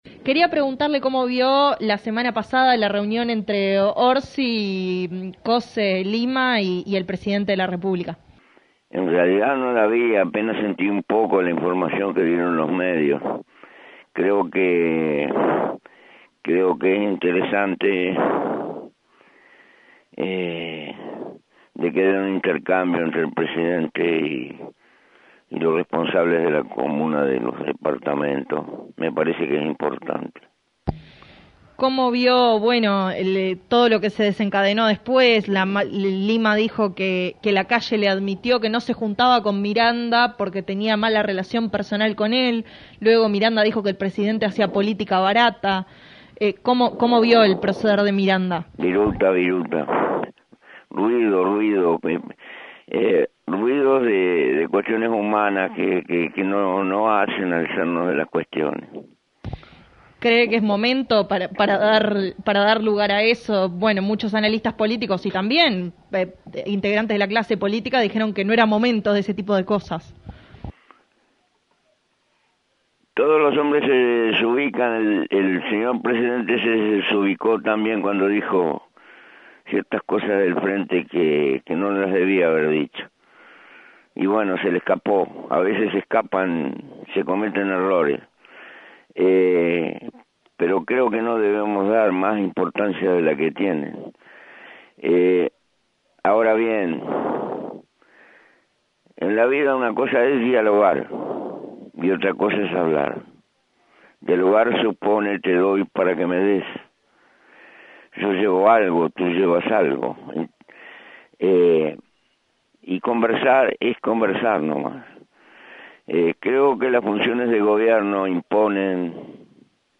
En diálogo con 970 Noticias, el expresidente José Mujica expresó que «es probable» que Miranda se haya «desubicado».